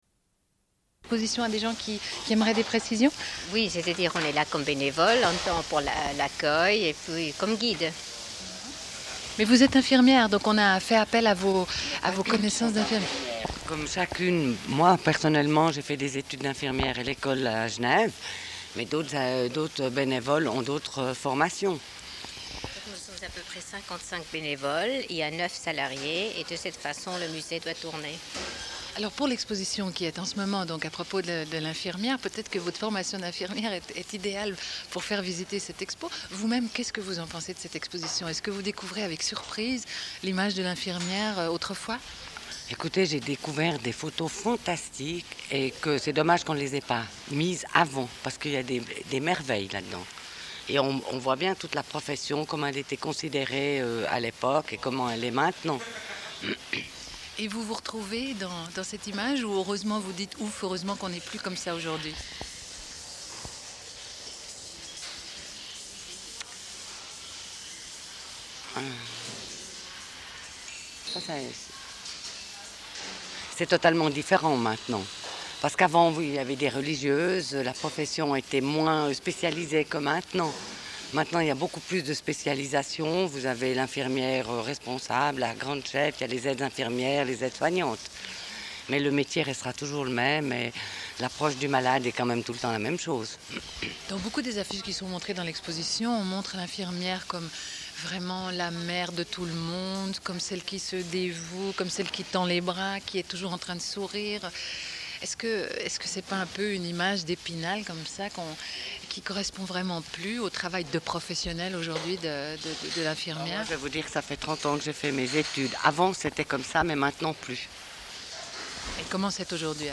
Une cassette audio, face A31:11